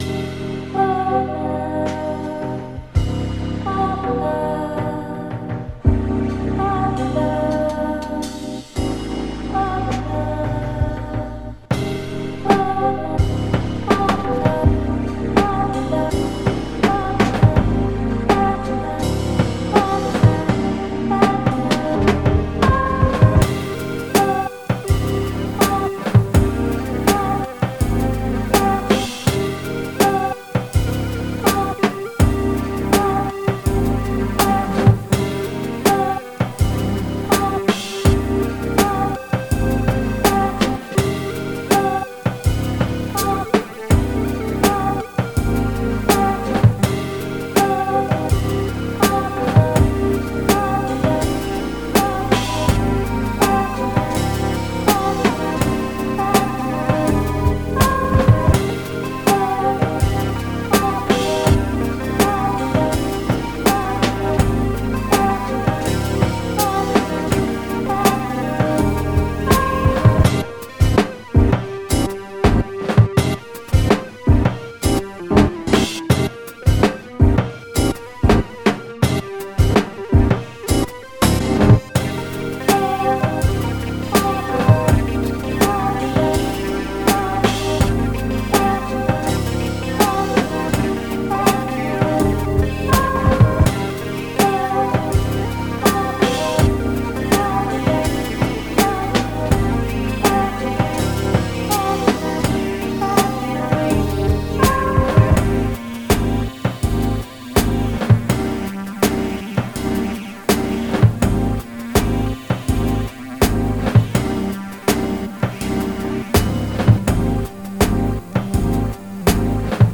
Home > Music > Pop > Medium > Laid Back > Floating